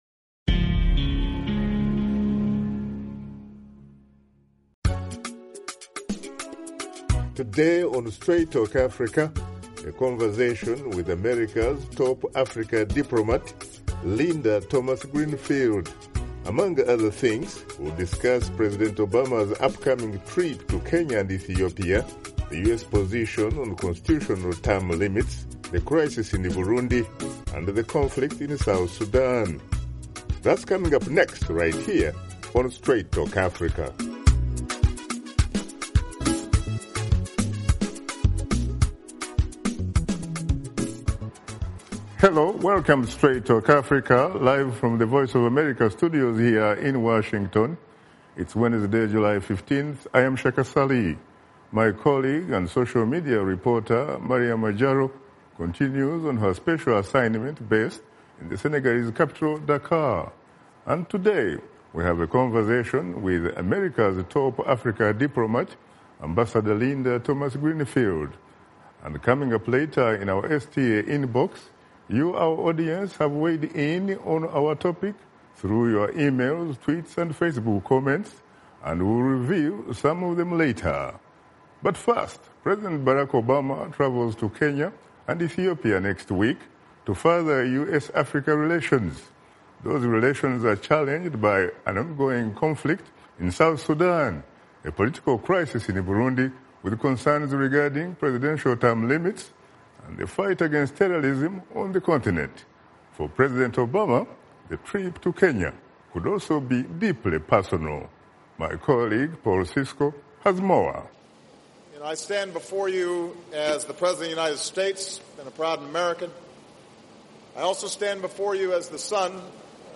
Host Shaka Ssali sits down with America's Top Diplomat for Africa prior to U.S. President Barack Obama's trip to Kenya to speak at the Global Entrepreneurship Summit and meet with African Union officials in Ethiopia. Washington Studio Guest: Ambassador Linda Thomas Greenfield, U.S. Assistant Secretary of State for African Affairs